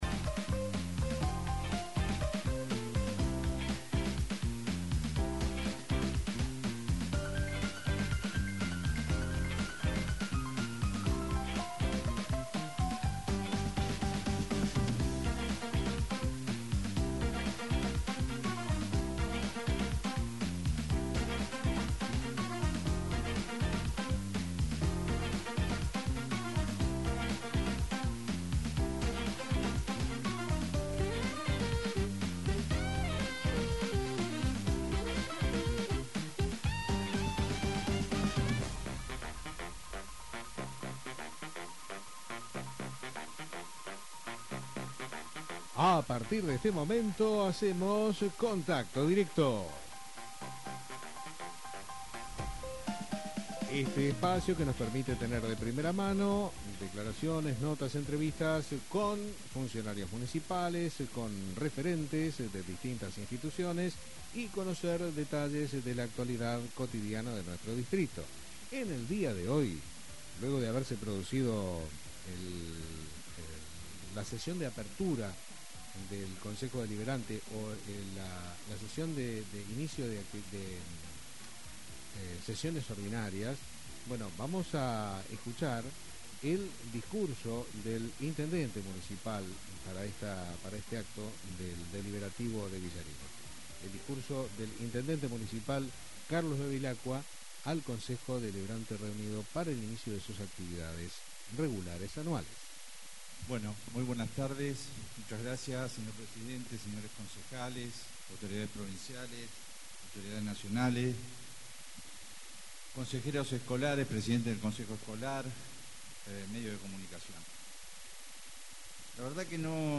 Se llevó a cabo la apertura del Período de Sesiones Ordinarias del Honorable Concejo Deliberante de Villarino.
A continuación, el intendente Dr. Carlos Bevilacqua presentó su mensaje anual, en el que repasó los principales avances de la gestión y delineó los ejes de trabajo para el 2025. En su discurso, el jefe comunal hizo especial hincapié en la digitalización del Municipio como una herramienta clave para mejorar la eficiencia de los servicios y facilitar los trámites a los vecinos.
cARLOSBEVILAQUADISCURSO.mp3